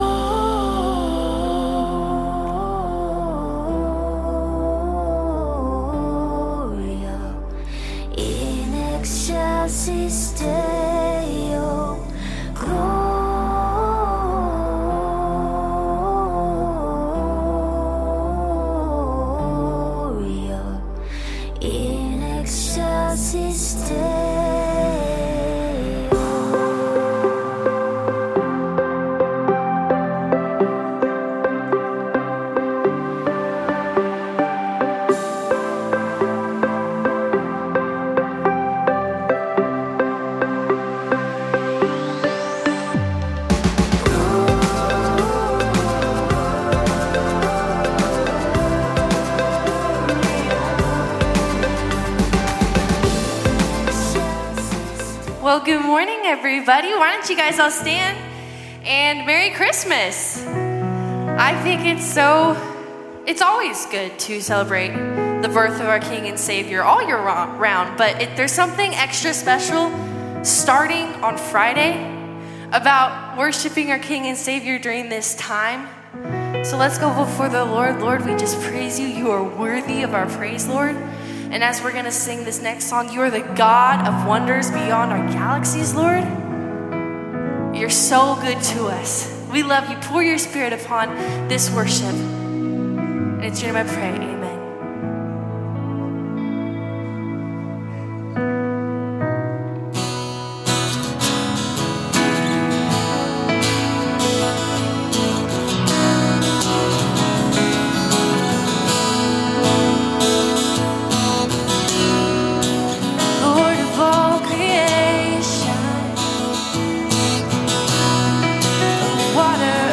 Calvary Knoxville Sunday AM Live!